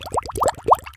Bubbles